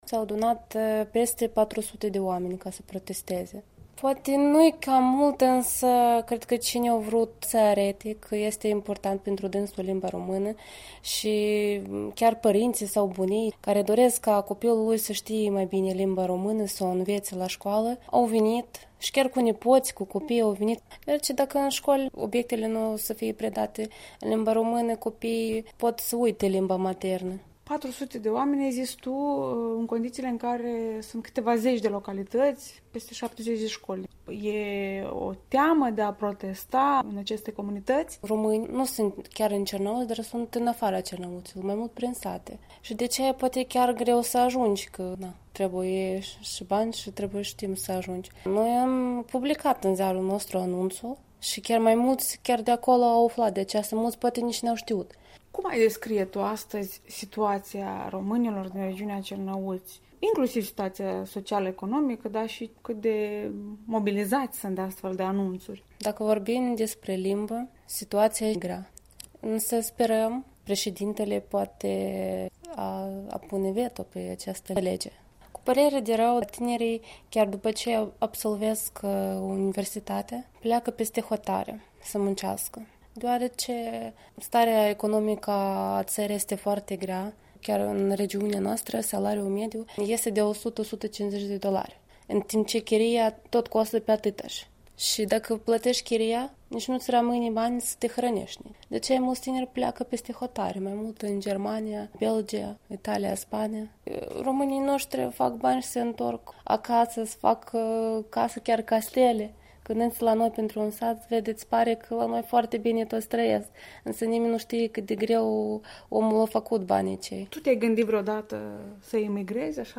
Un interviu cu o ziaristă de la publicaţia „Zorile Bucovinei” despre protestul românilor din Cernăuți împotriva noii legi a educației din Ucraina.